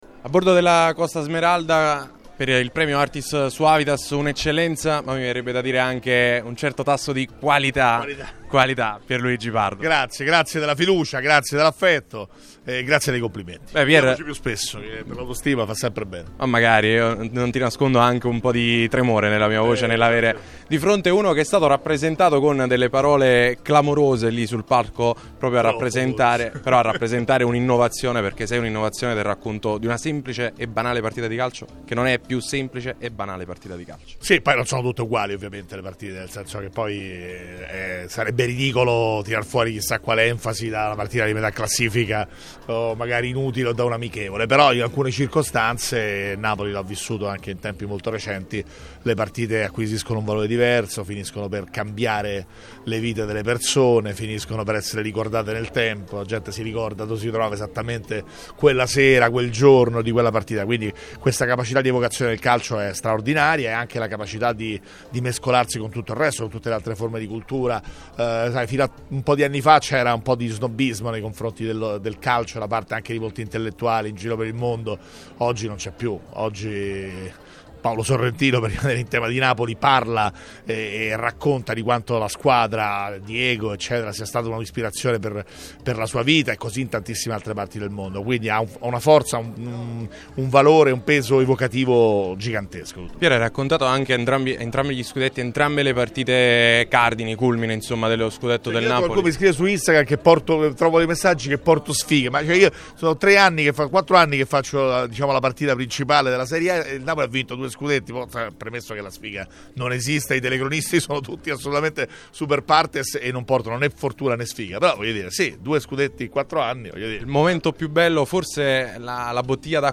Ospite di Radio Punto Nuovo in occasione della conferenza stampa di presentazione del Premio Artis Suavitas a bordo della Costa Smeralda, il telecronista DAZN Pierluigi Pardo.